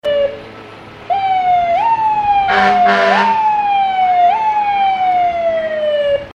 Downtown Manhattan lights up for annual Spirit of the Holidays parade
Loud parade entries included other emergency vehicles from Manhattan and Riley County Fire Departments, Riley County EMS, and other agencies.
1202-MHK-Parade-Fire-Sirens.mp3